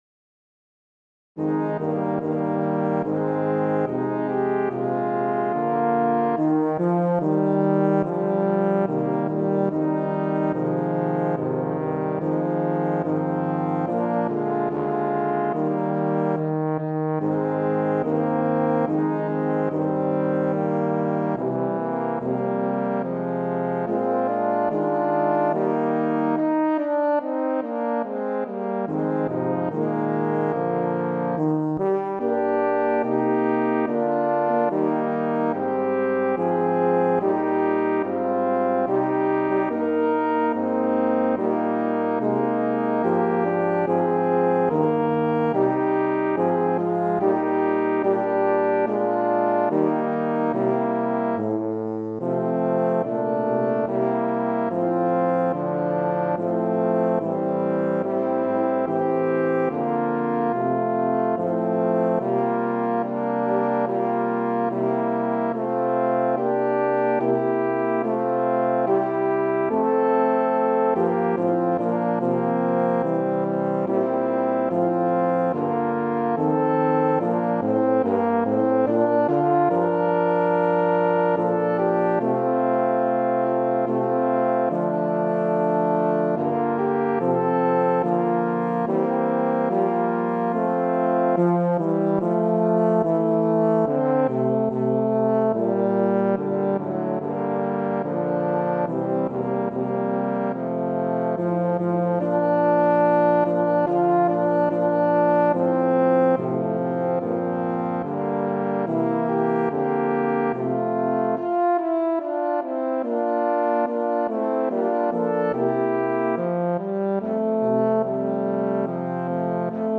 Voicing: Tuba Quartet (EETT)